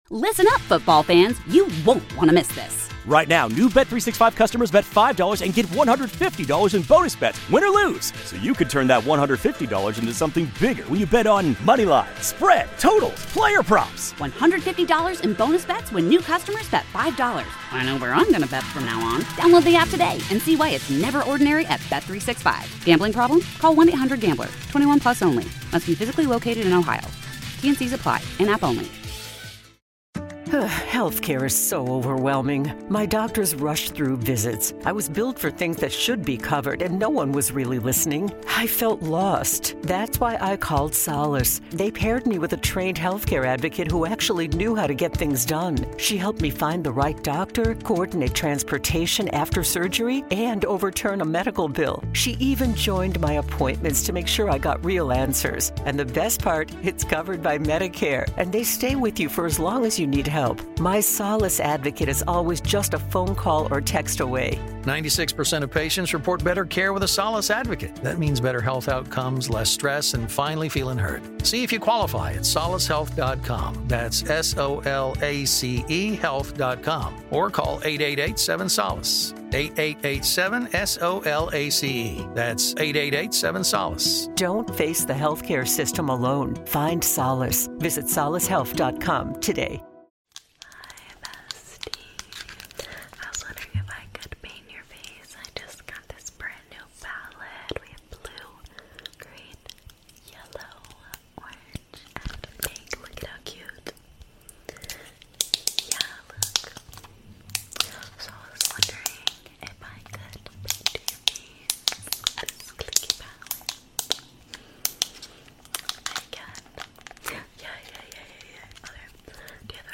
ASMR CLICKY SPIT PAINTING 🎨 TOO LOUD??